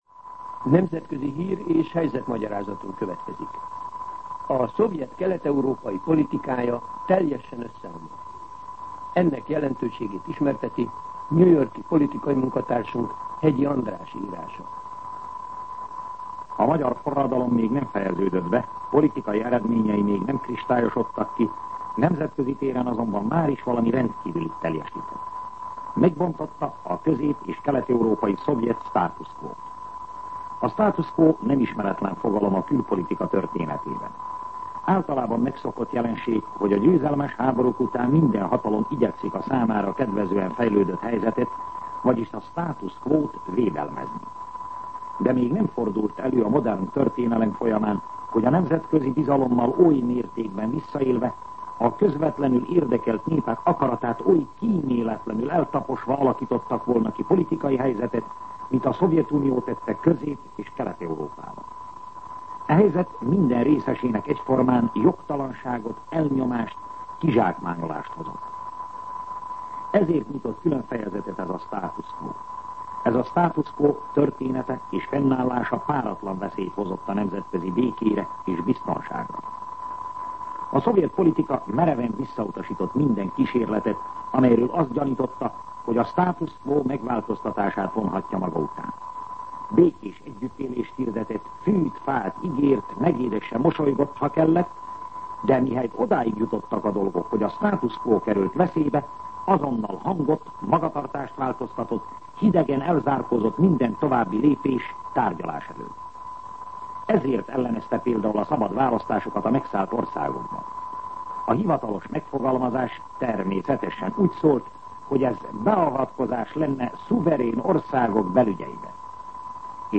Nemzetközi hír- és helyzetmagyarázat